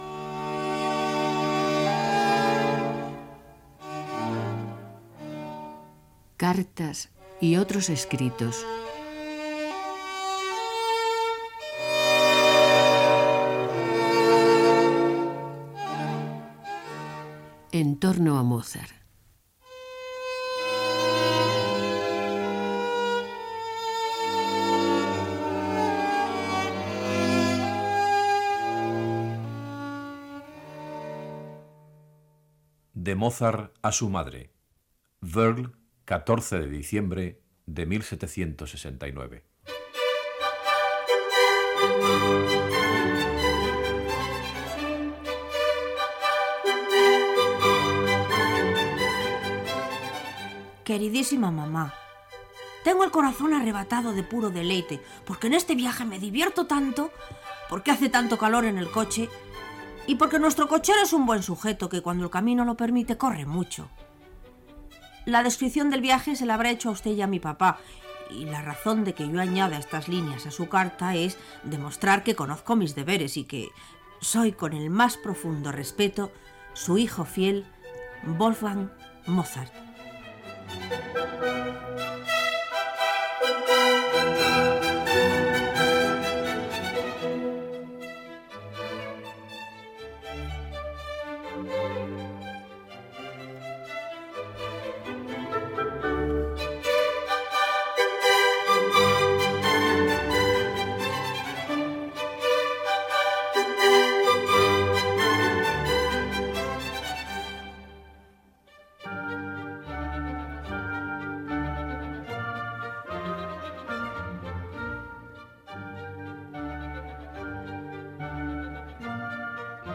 Careta del programa, lectura d'una carta de Mozart a la seva mare i d'altres escrits sobre el compositor austríac Gènere radiofònic Musical